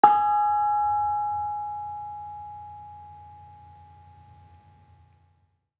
Bonang-G#4-f.wav